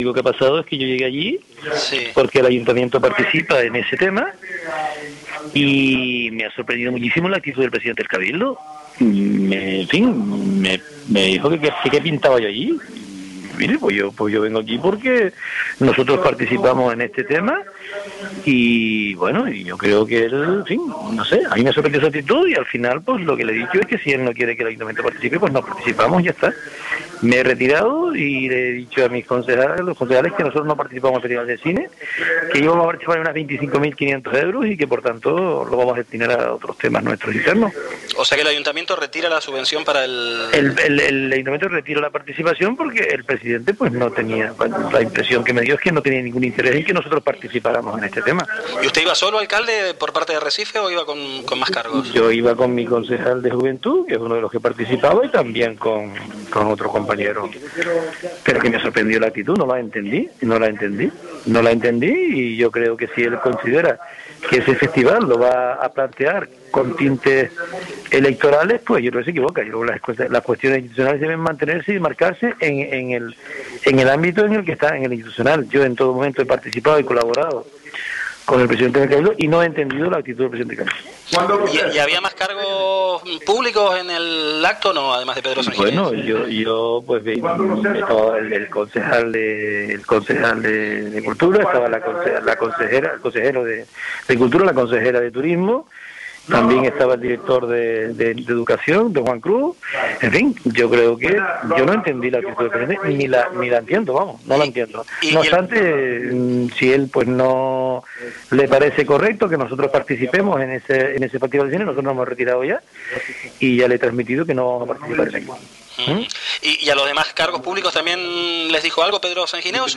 Declaraciones-de-Candido-Reguera.mp3